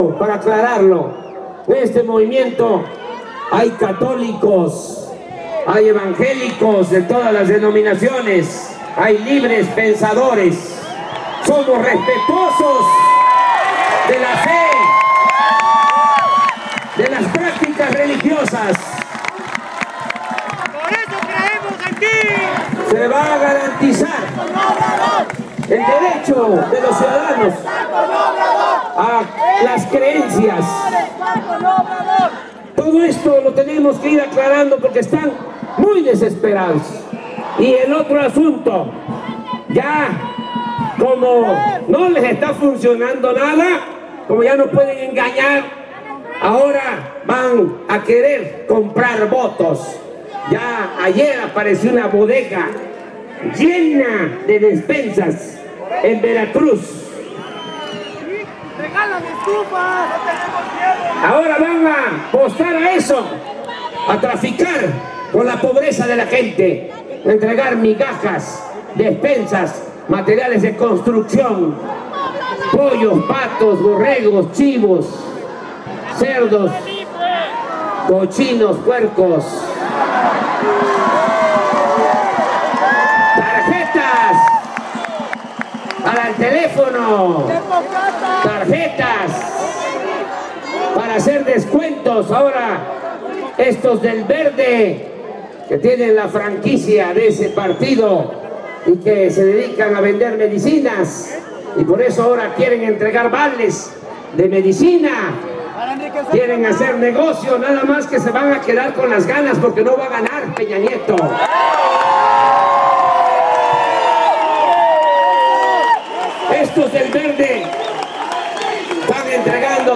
Discurso de Obrador (solo audio)